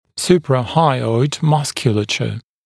[ˌsuprə’haɪɔɪd ‘mʌskjuləʧə][ˌсупрэ’хайойд ‘маскйулэчэ]надподъязычная мускулатура